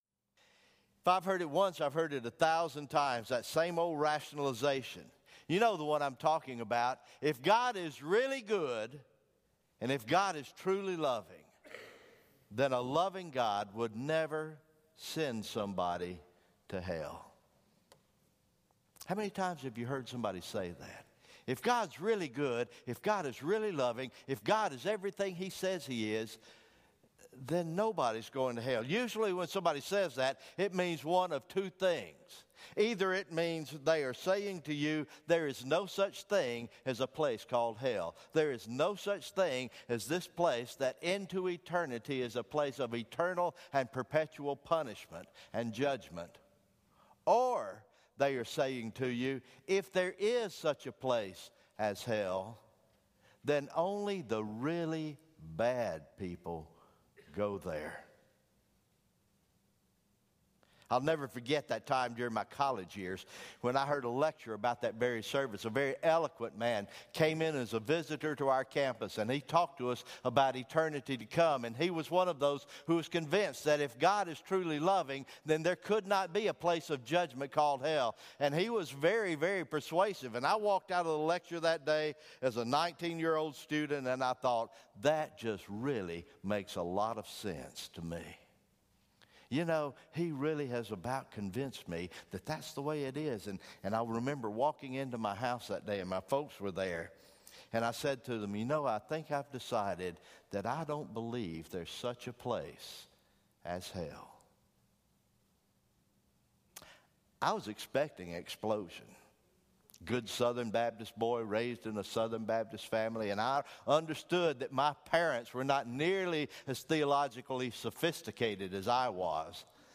February 5, 2017 Morning Worship